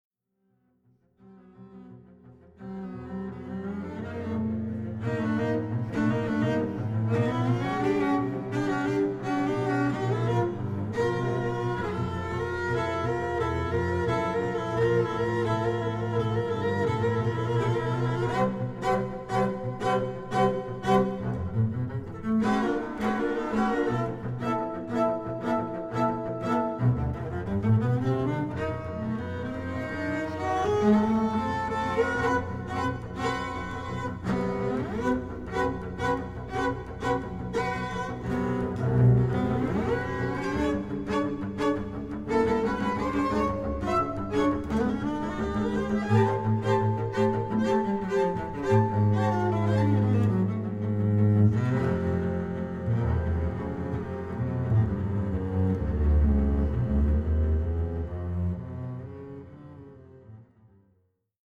Please note: These samples are not of CD quality.
Quartet for Basses